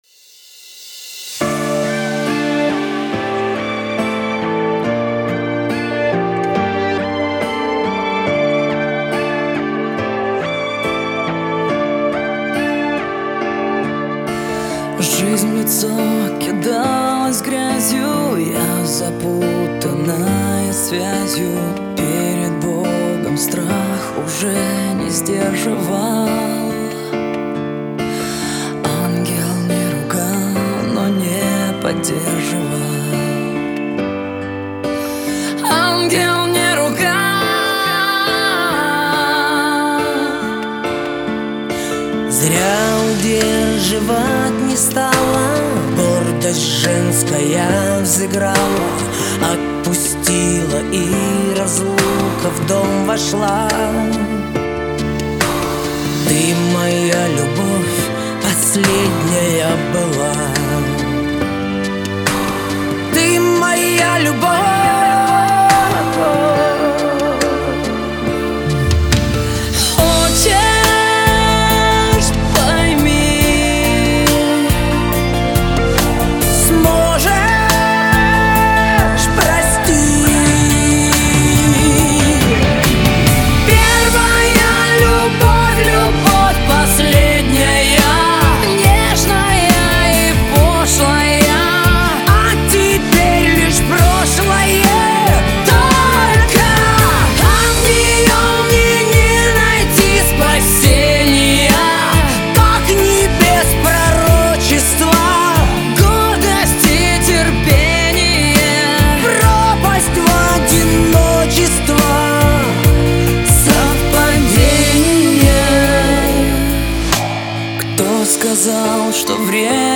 Категория: Грустные песни